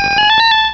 Cri de Porygon2 dans Pokémon Rubis et Saphir.